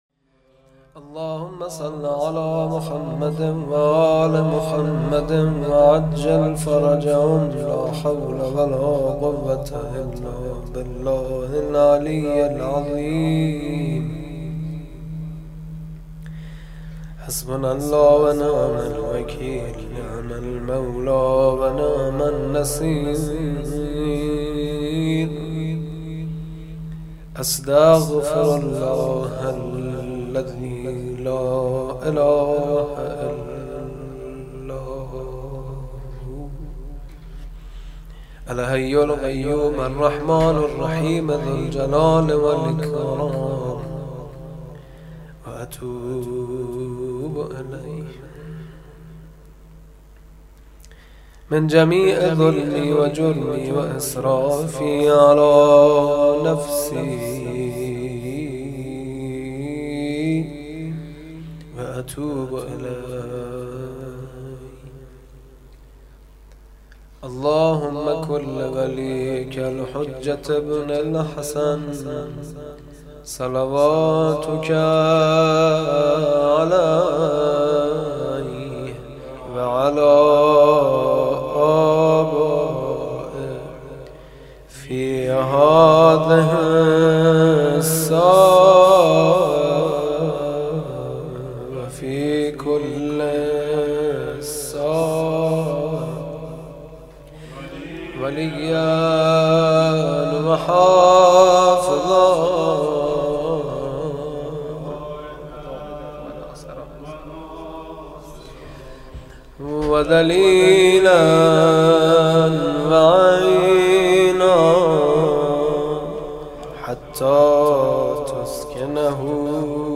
توضیحات: جشن مبعث پیامبر اعظم (ص)؛ مناجات با امام زمان (عج)